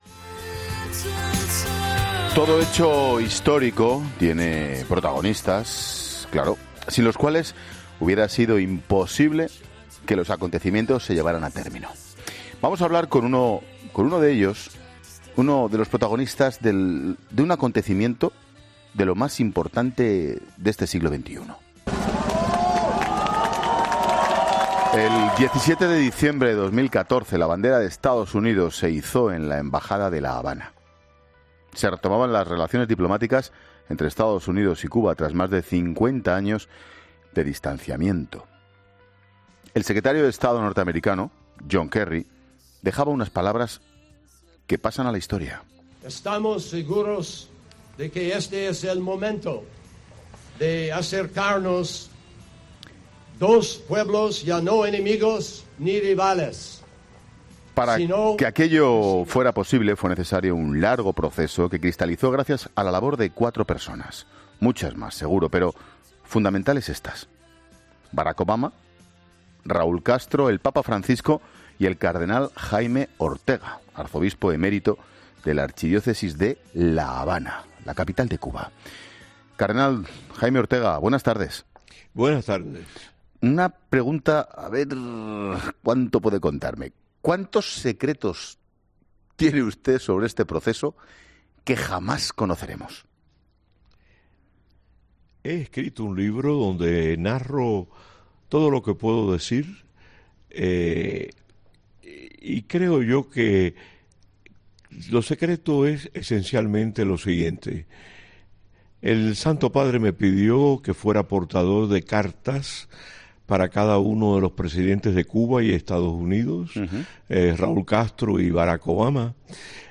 ESCUCHA LA ENTREVISTA COMPLETA | Arzobispo emérito de La Habana, Jaime Ortega, en 'La Tarde'